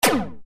bl_smg_shoot.ogg